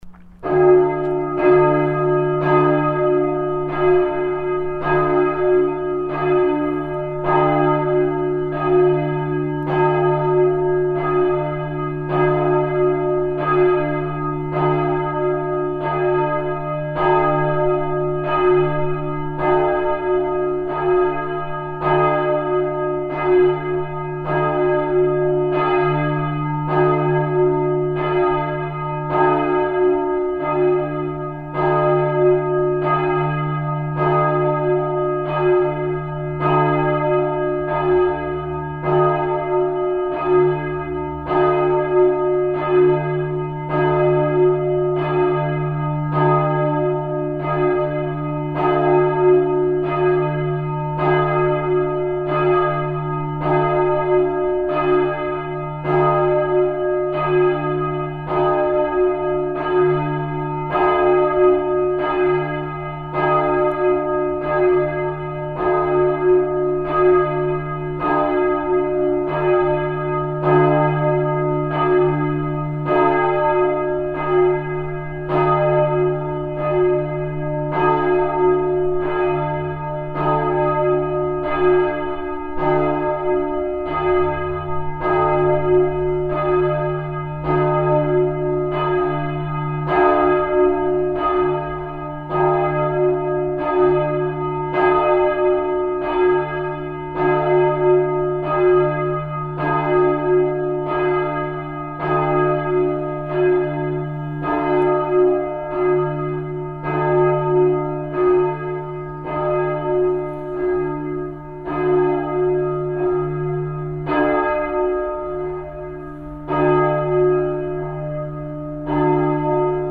Da St. Lorenz vier verschiedene Glocken besitzt (nämlich mit den Nominalen, also gehörten Tönen, d1, f1, g1 und b1), ergeben sich daraus verschiedene Läut-Kombinationsmöglichkeiten, die je nach Anlass zum Tragen kommen und die in der Läuteordnung für St. Lorenz festgelegt sind.
Wie jede Glocke für sich klingt können sie hier nachhören:
02-Glocke-1.mp3